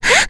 Kirze-Vox_Jump.wav